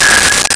Megaphone Feedback 02
Megaphone Feedback 02.wav